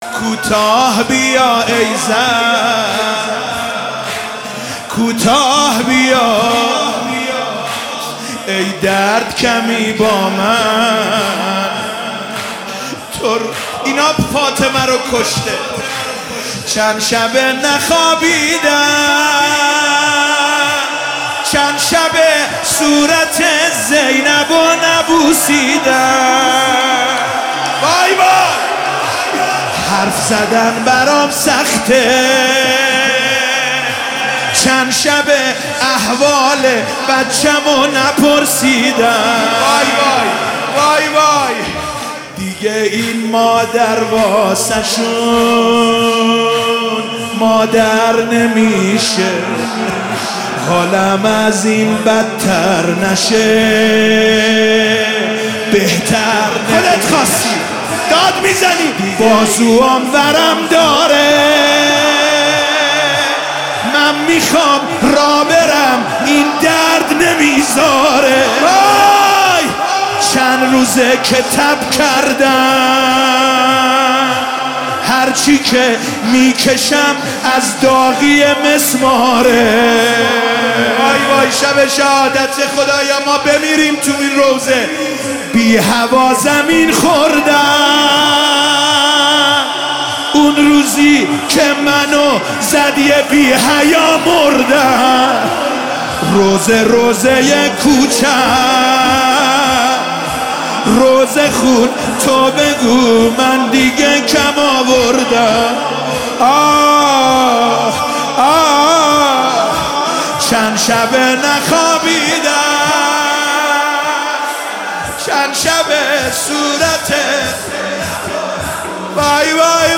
کلیپ مداحی سوزناک فاطمیه ویژه شهادت حضرت زهرا ایام فاطمیه 1402